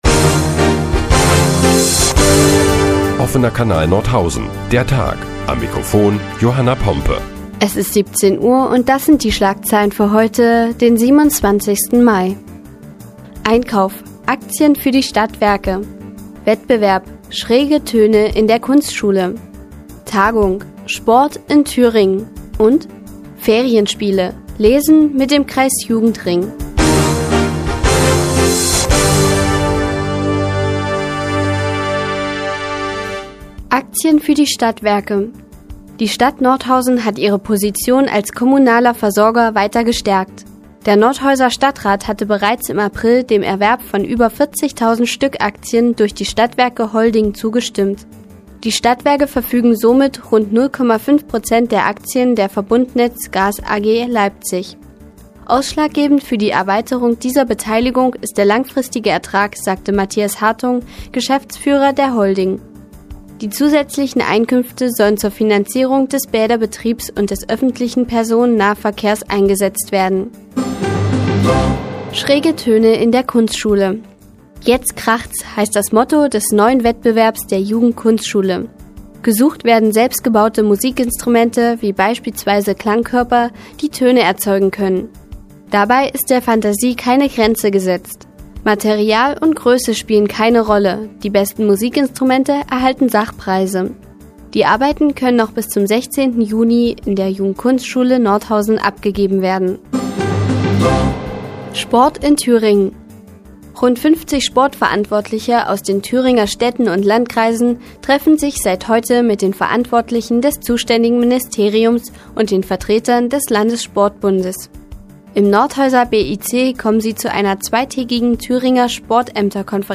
Die tägliche Nachrichtensendung des OKN ist nun auch in der nnz zu hören. Heute geht es unter anderem um schräge Töne in der Kunstschule und Sport in Thüringen.